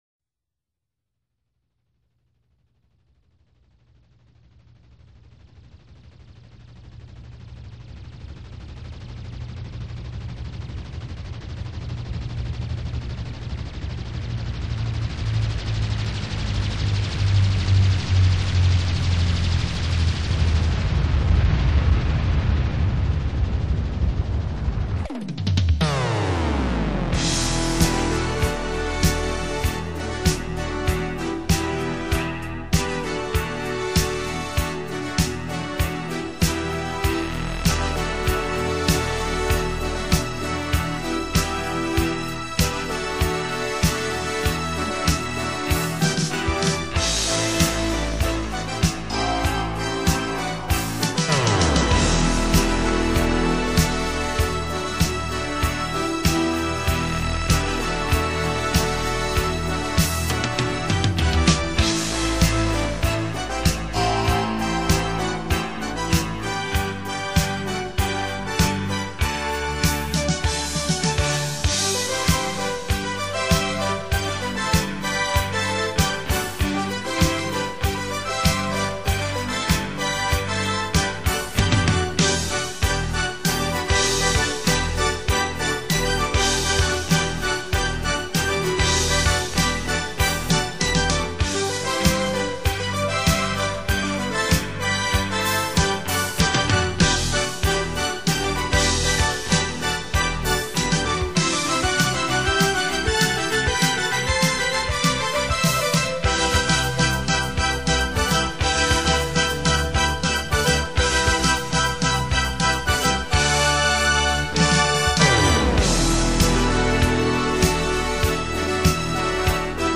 新世纪音乐